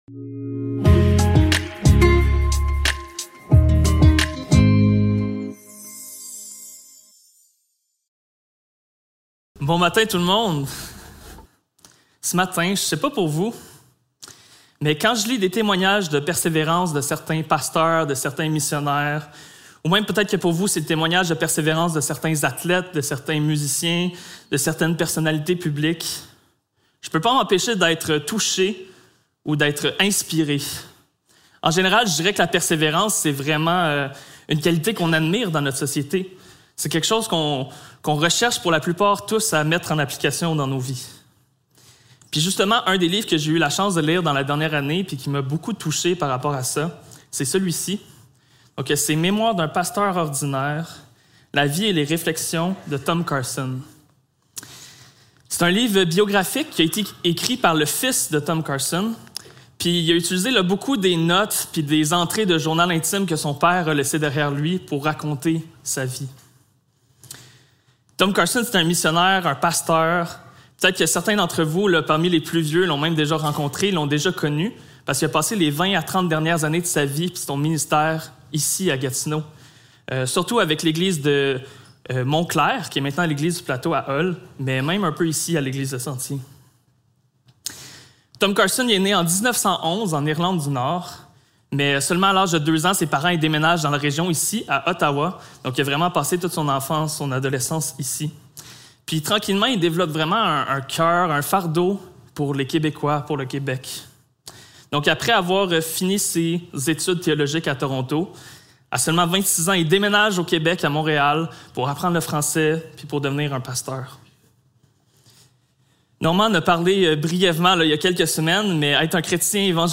Apocalypse 3.7-13 Service Type: Célébration dimanche matin Description